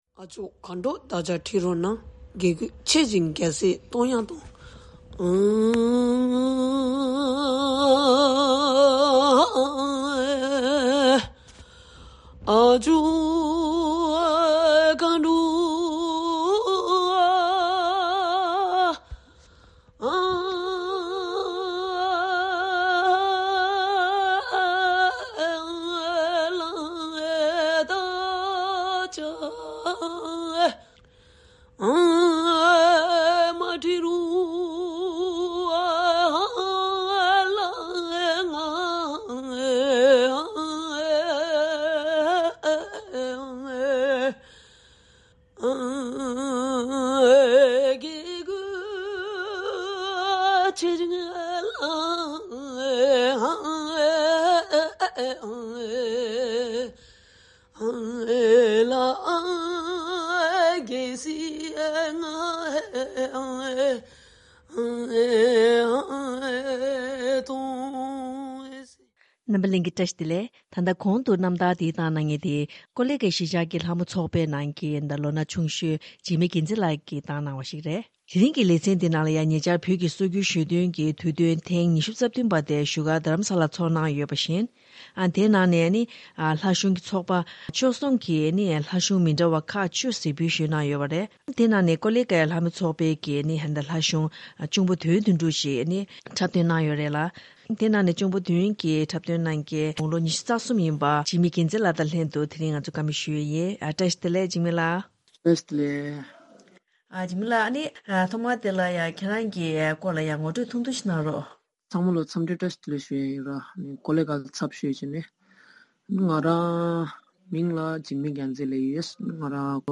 བཀའ་འདྲི་ཕྱོགས་བསྡུས་ཞུས་པའི་གནས་ཚུལ།